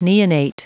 Prononciation du mot neonate en anglais (fichier audio)
Prononciation du mot : neonate